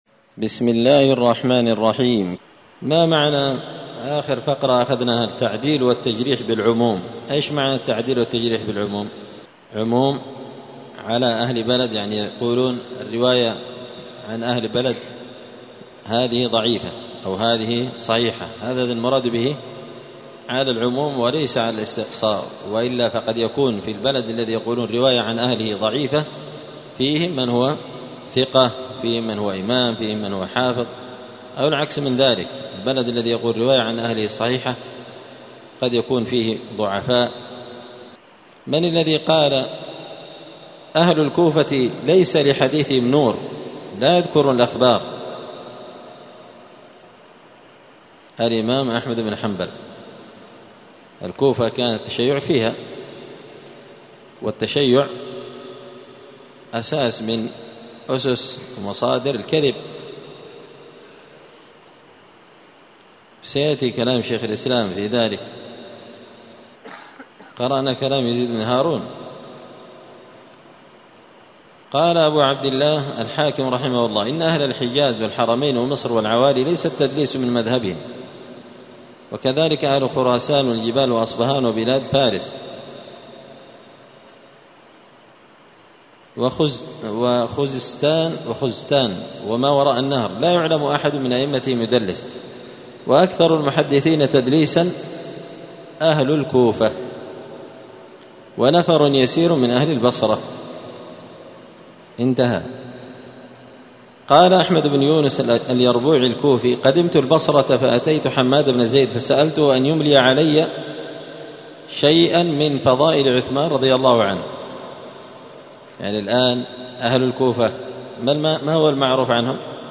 *الدرس الثامن والخمسون (58) تابع لباب التعديل والتجريح بالعموم.*